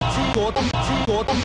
ちょんちょん.wav